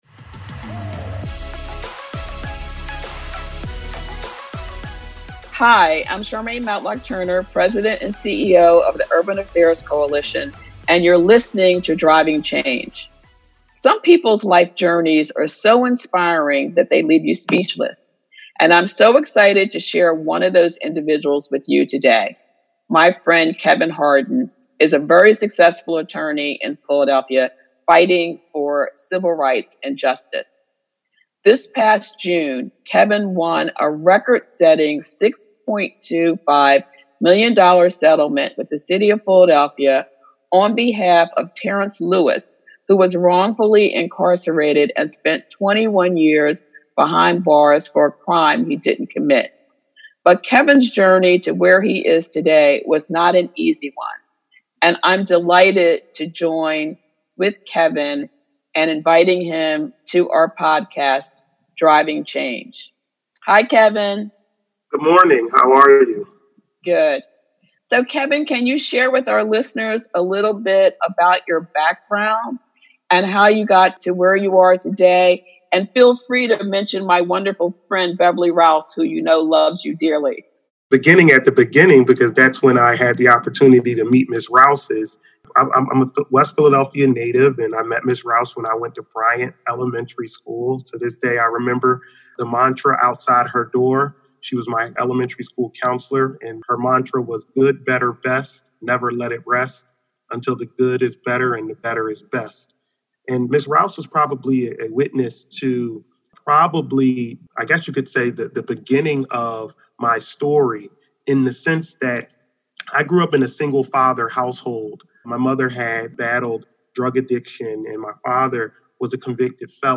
Driving Change Conversation